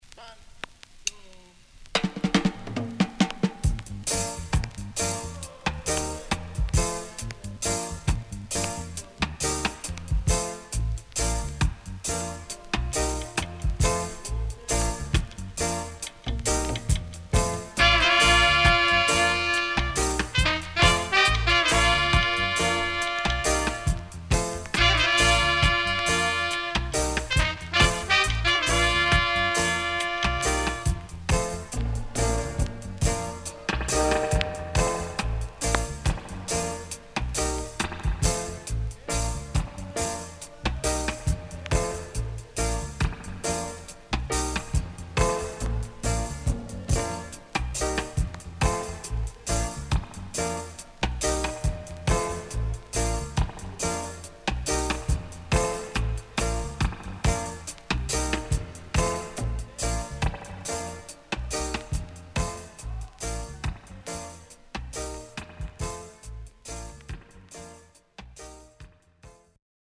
HOME > REGGAE / ROOTS  >  RECOMMEND 70's  >  INST 70's
Nice Horn Inst
SIDE A:かるいヒスノイズ入ります。